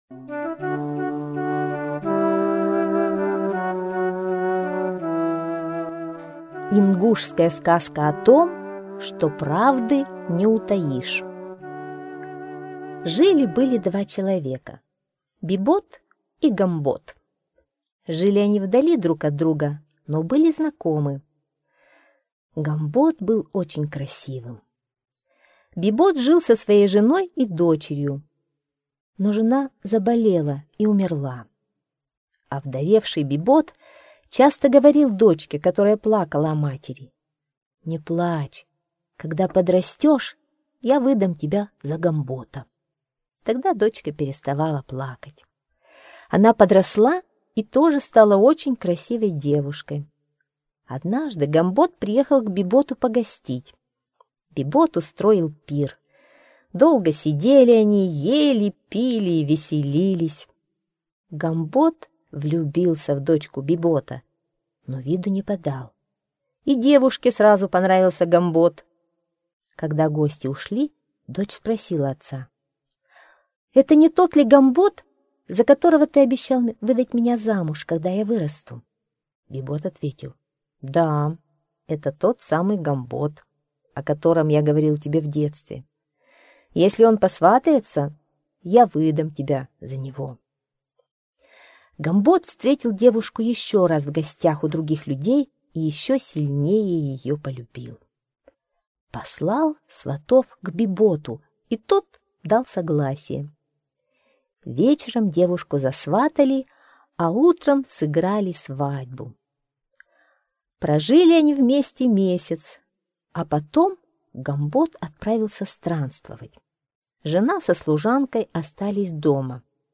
Сказка о том, что правды не утаишь - ингушская аудиосказка - слушать